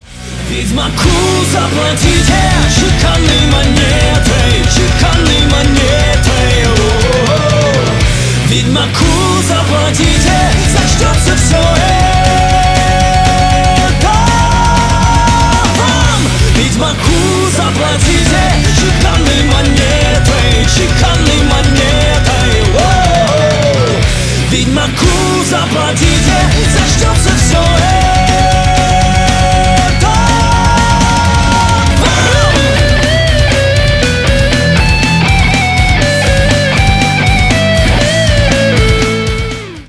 Рок Металл
громкие # кавер